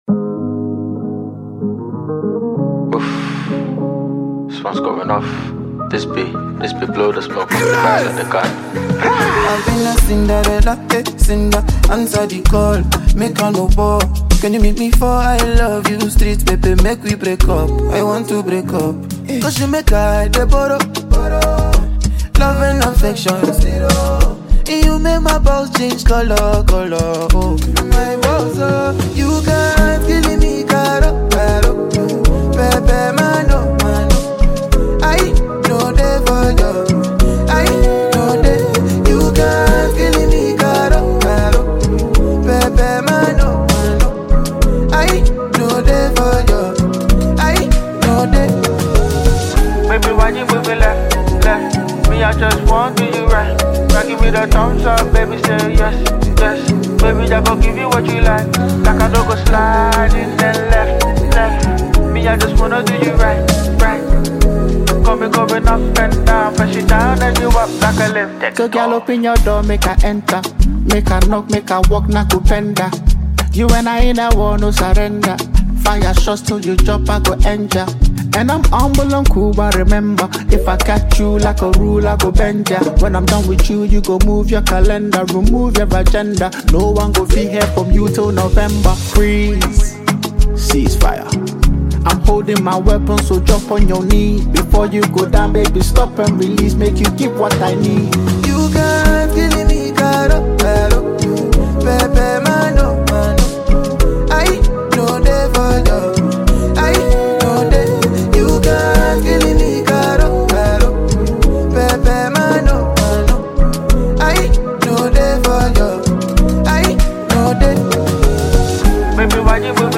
joyful track